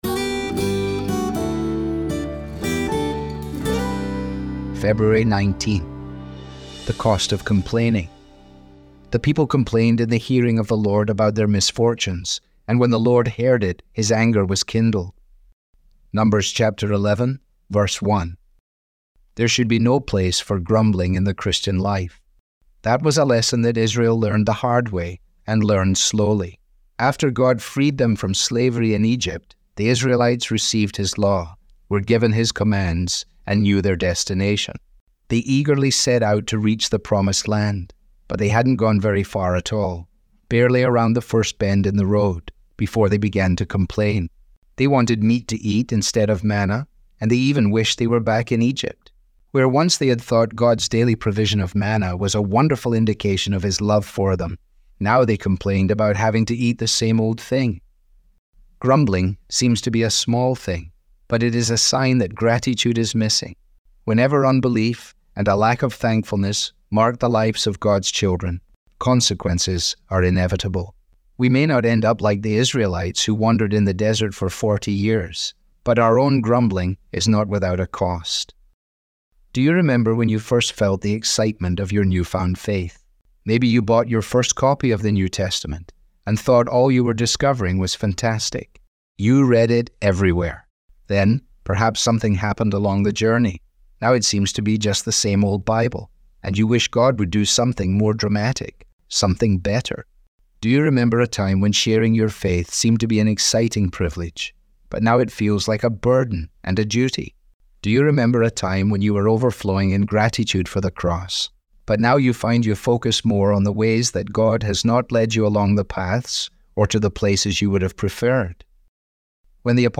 Audio was digitally created by Truth For Life with permission.